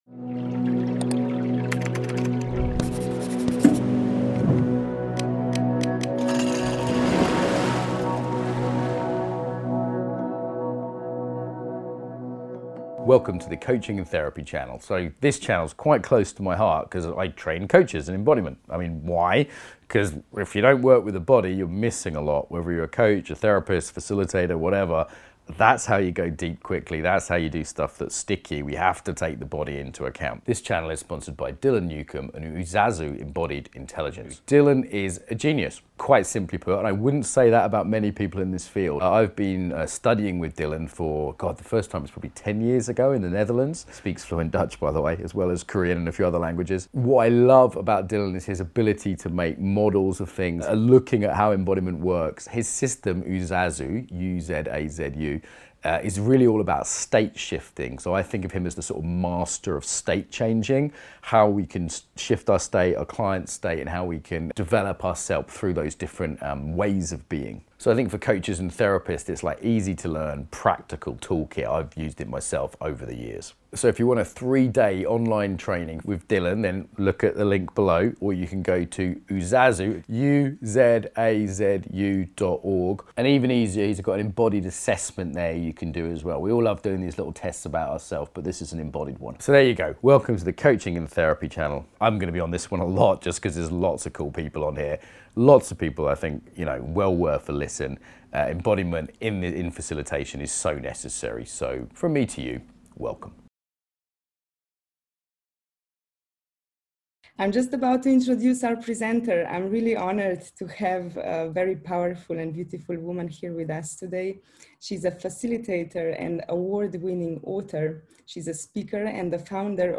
Open to all Movement not required Likely soothing One November morning a few years ago